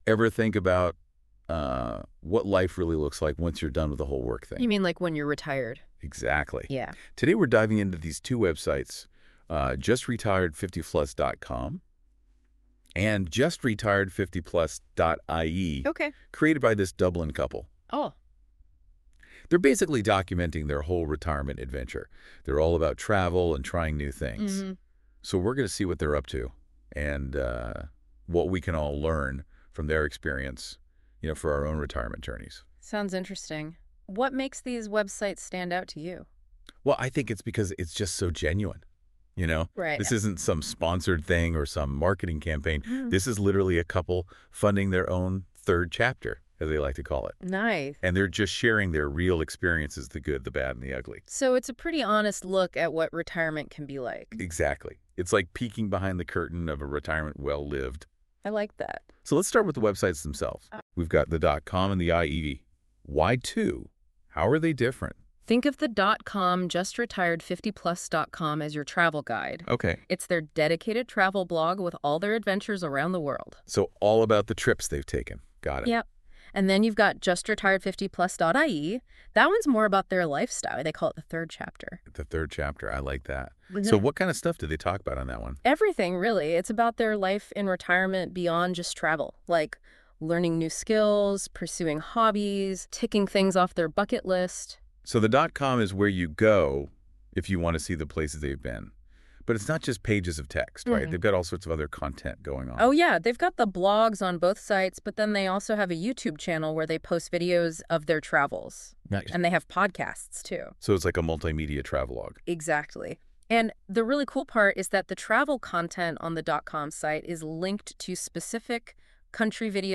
A cartoon image of a studio PodCast conversation link to an audio podcast.
Our JustRetired50Plus websites blog structure explained in the conversational PodCast, access via the image above